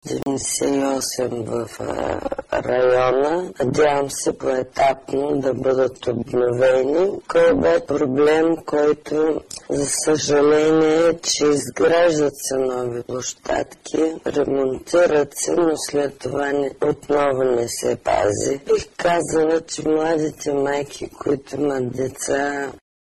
I have also attached the reversed Bulgarian file used in the experiment which as you will see is only 23 seconds long.
Reversed Bulgarian background file
Reversed-bulgarian-experiment-file.mp3